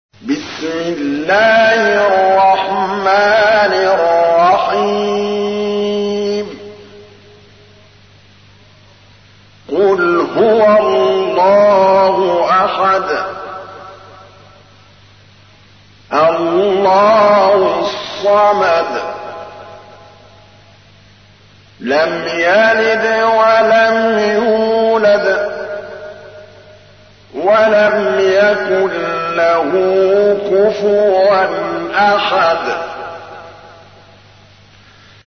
تحميل : 112. سورة الإخلاص / القارئ محمود الطبلاوي / القرآن الكريم / موقع يا حسين